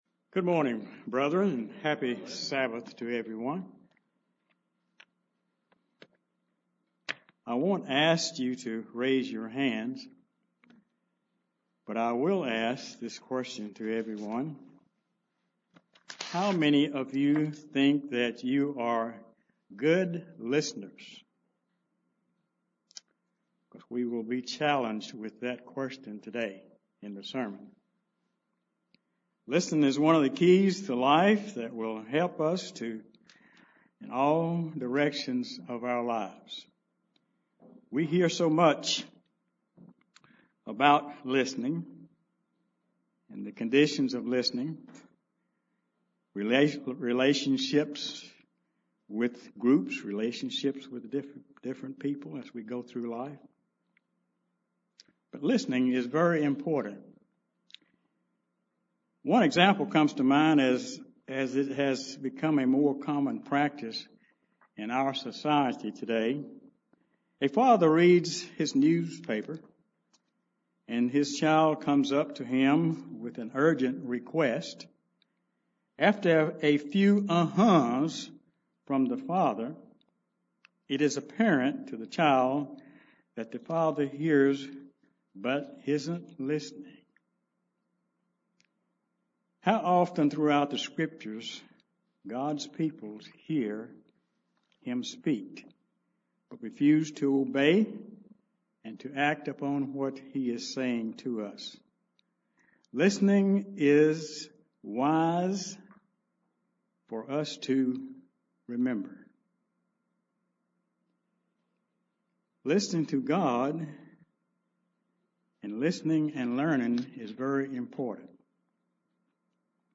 UCG Sermon Studying the bible?
Given in Charlotte, NC